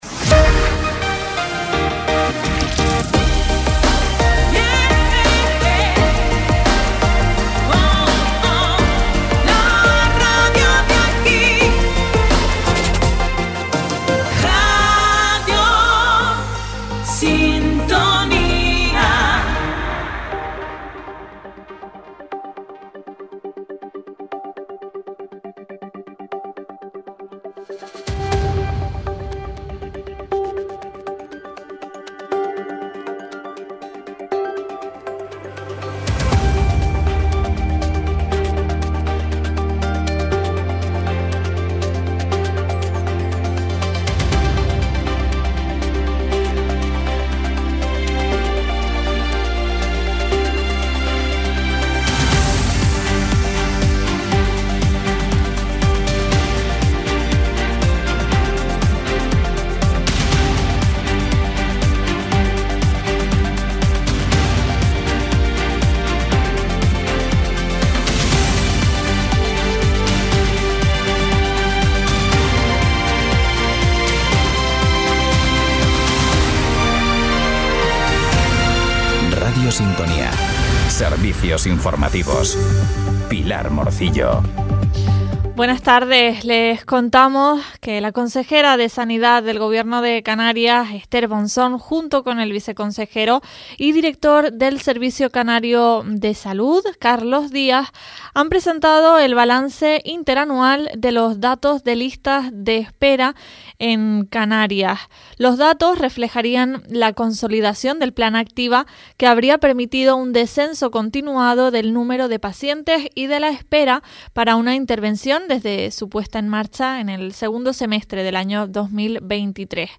En Radio Sintonía Fuerteventura les mantenemos al día de la actualidad local y regional en nuestros informativos diarios a las 13.15 horas. Por espacio de 15 minutos acercamos a la audiencia lo más destacado de los distintos municipios de la isla, sin perder la atención en las noticias regionales de interés general.